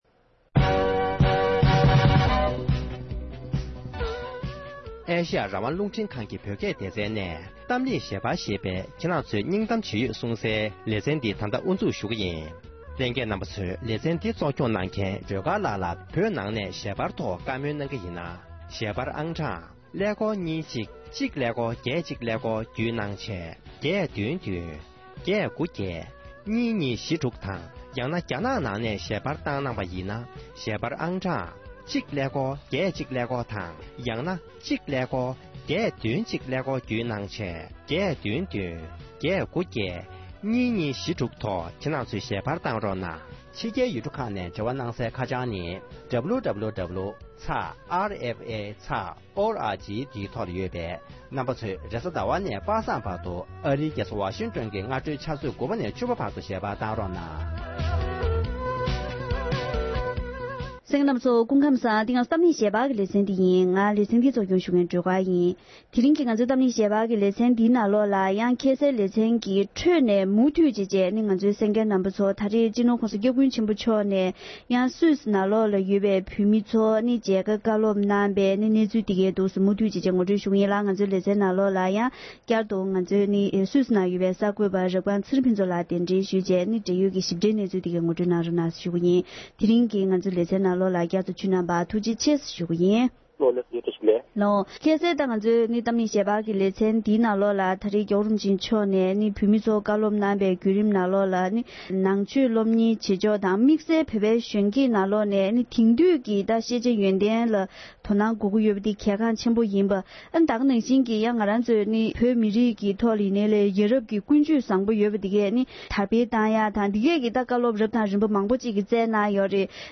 གླེང་མོལ་གནང་བར་མུ་མཐུད་གསན་རོགས༎